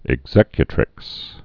(ĭg-zĕkyə-trĭks)